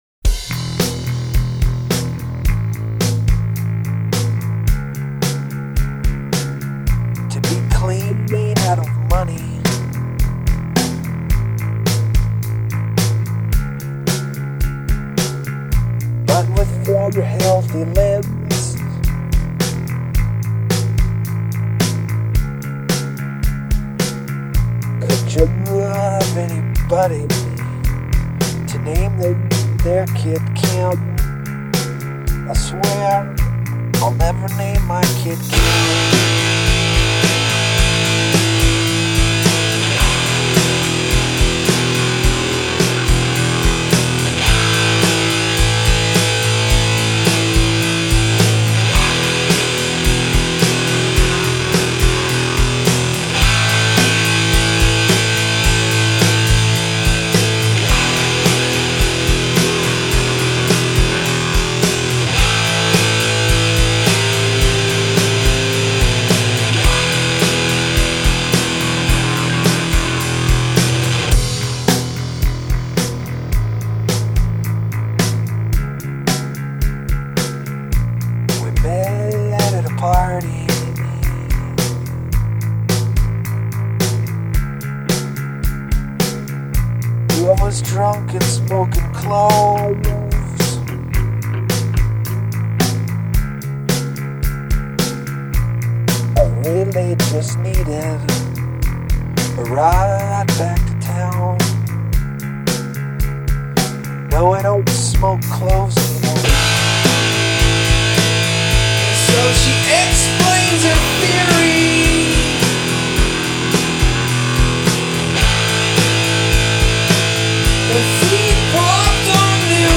assez rock mais confidentiel…